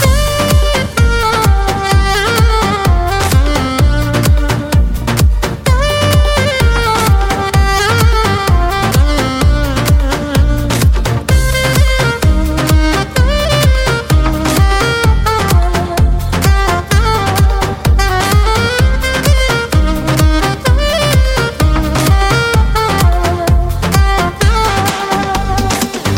Kategorie Remixy